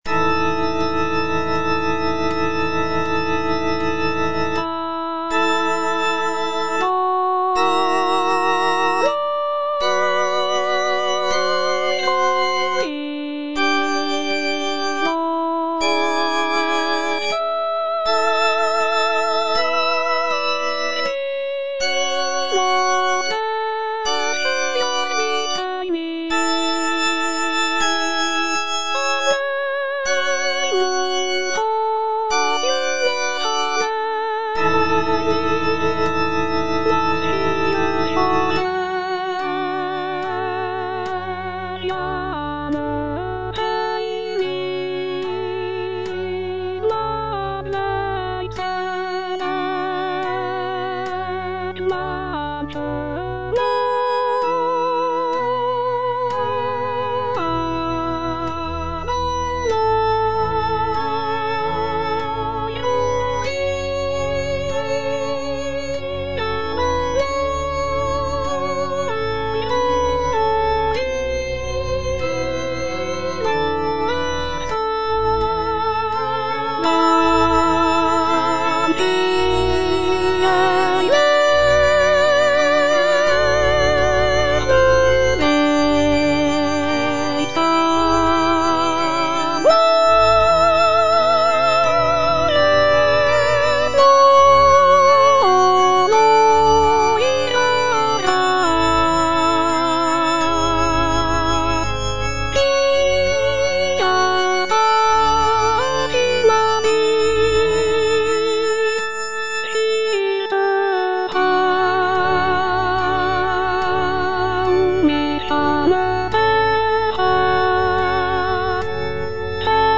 (soprano I) (Voice with metronome) Ads stop